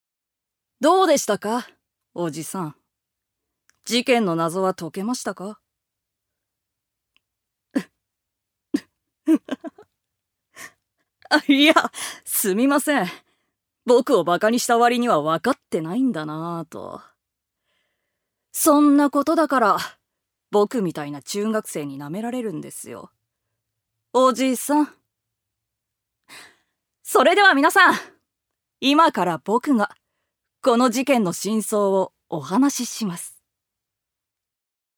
預かり：女性
セリフ２